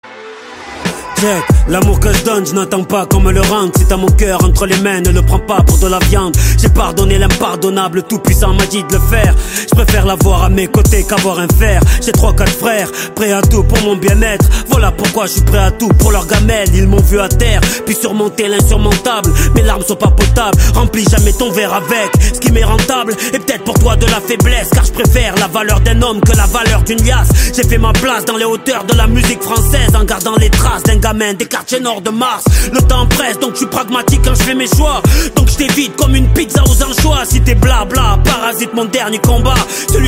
Catégorie Rap